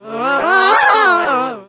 stu_hurt_vo_02.ogg